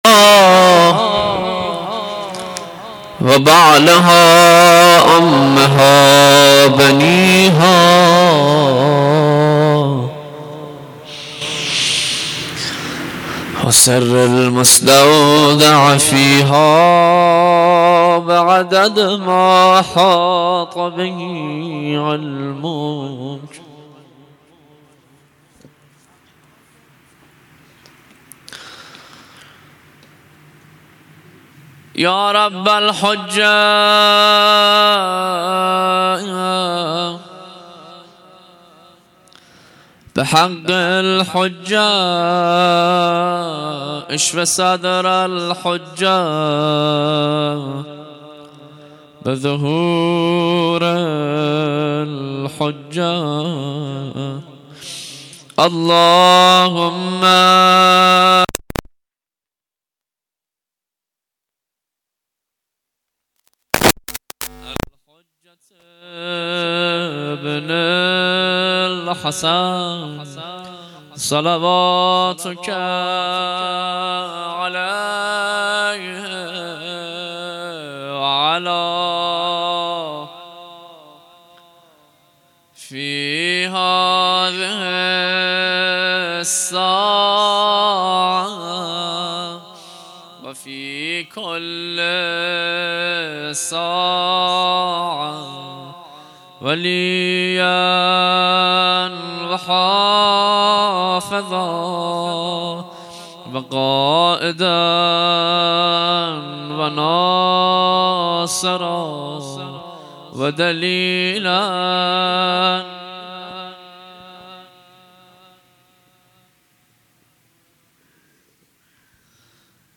هیئت انصار سلاله النبی
زیارت عاشورا
محرم 1442 شب پنجم